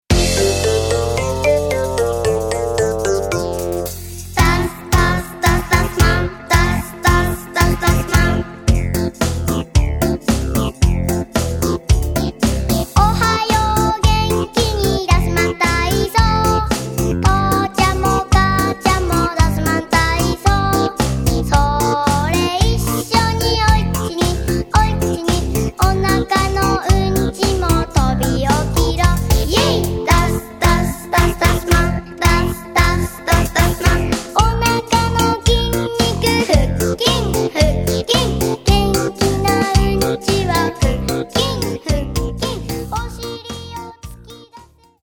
歌の試聴カラオケ版